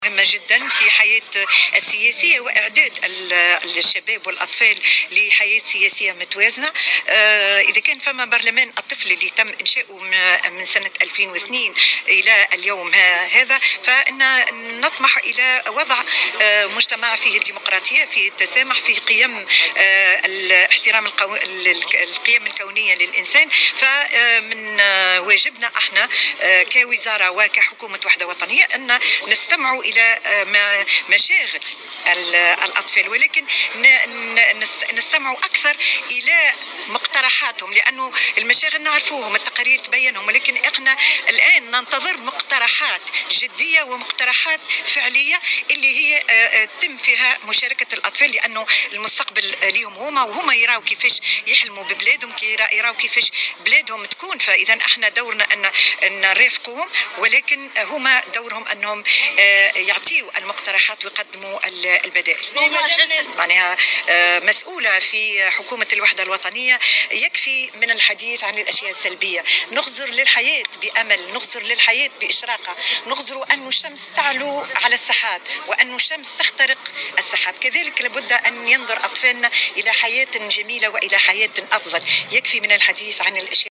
أكدت وزيرة المرأة والأسرة والطفولة نزيهة العبيدي في تصريح لمراسل الجوهرة" اف ام" اليوم الإثنين على هامش جلسة عامة افتتاحية لبرلمان الطفل أن هذا الحدث يعد لحظة مهمة في اعداد الشباب والأطفال لحياة سياسية متوازنة.